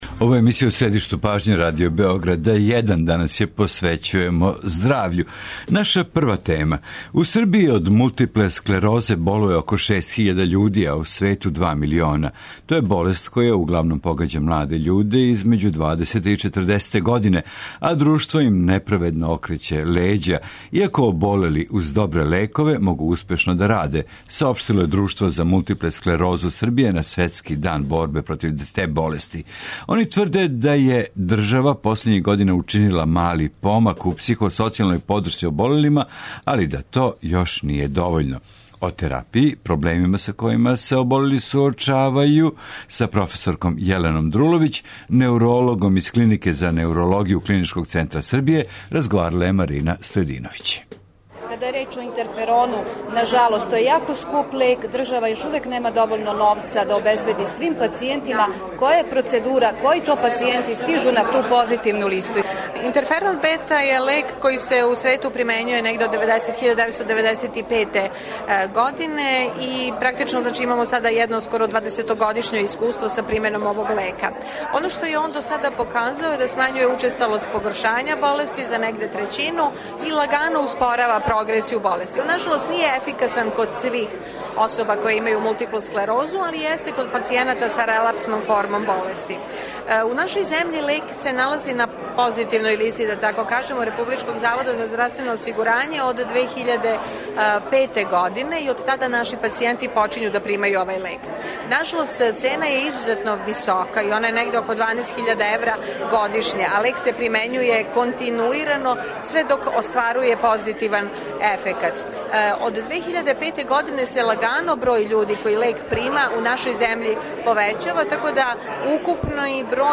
Наш гост ће радо дати савет и одговорити на свако ваше питање уколико се током емисије јавите на наше телефоне: